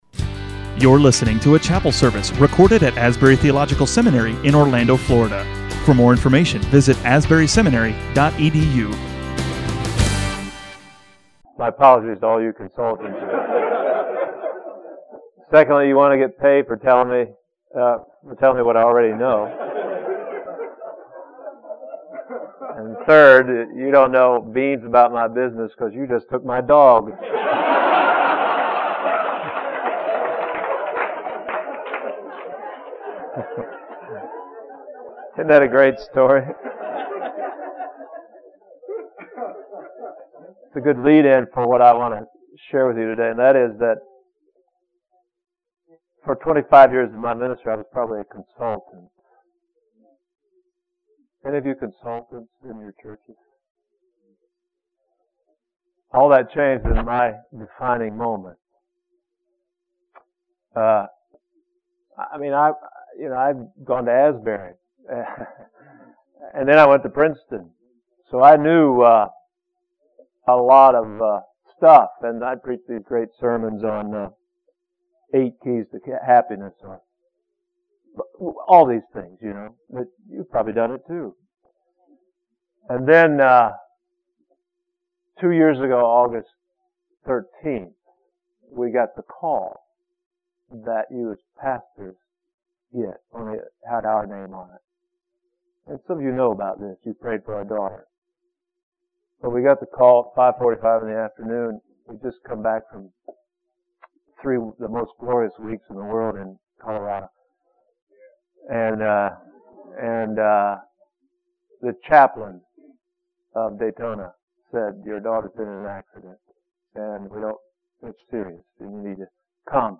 An address delivered at Asbury Theological Seminary Florida Campus chapel service, (2003, October 2)